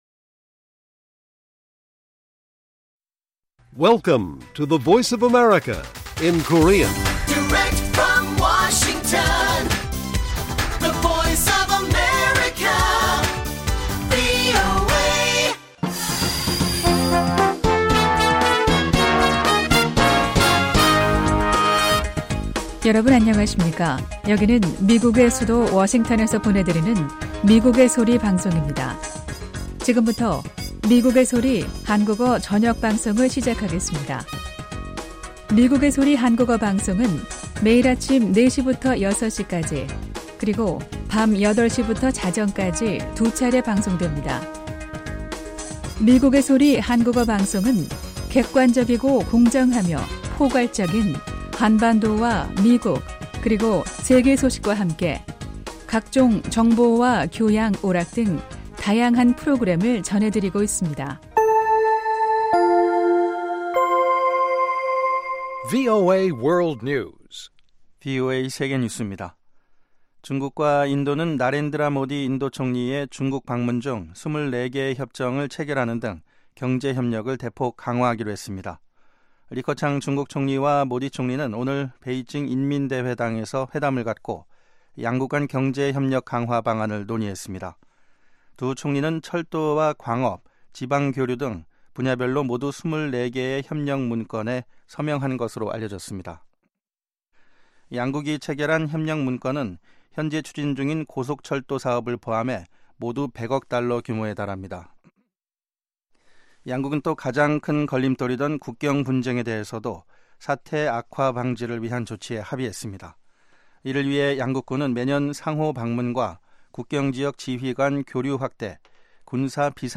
VOA 한국어 방송의 간판 뉴스 프로그램 '뉴스 투데이' 1부입니다. 한반도 시간 매일 오후 8시부터 9시까지 방송됩니다.